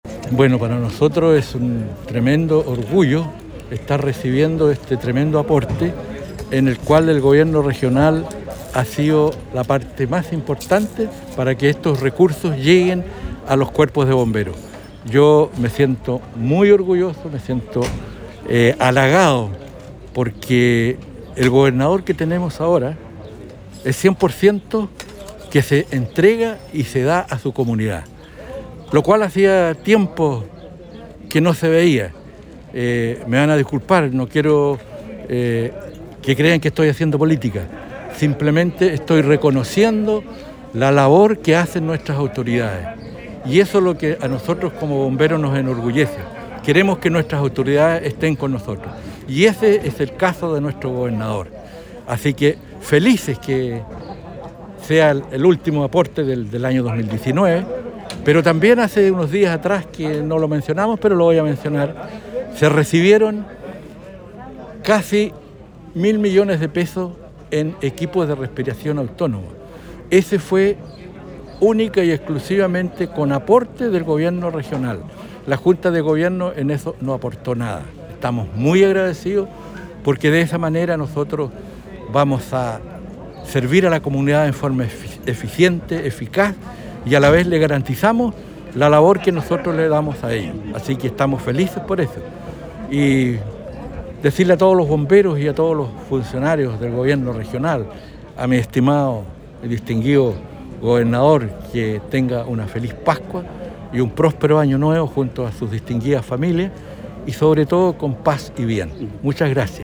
En una emotiva ceremonia, el Gobernador Regional de Los Ríos, Luis Cuvertino junto al Consejero Regional Elías Sabat entregaron un nuevo carro a la 3ra Compañía de Bomberos de Lanco, proyecto que tuvo una inversión de $171 millones, de los cuales $55 millones corresponden a un aporte de la Junta Nacional de Bomberos y $116 millones del GORE de Los Ríos, a través del Fondo Nacional de Desarrollo Regional (FNDR).